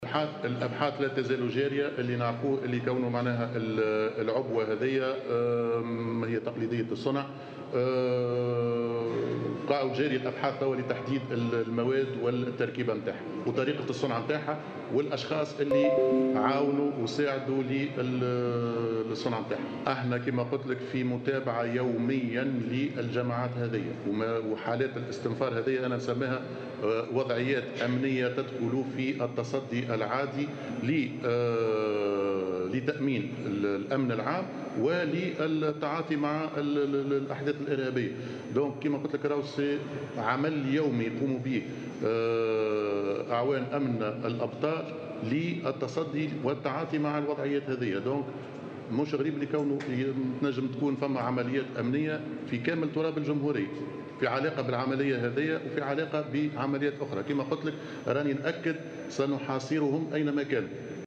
وأضاف الوزير خلال ندوة صحفية أن الأبحاث جارية لتحديد طريقة صنعها والأشخاص الذين ساعدوا في ذلك.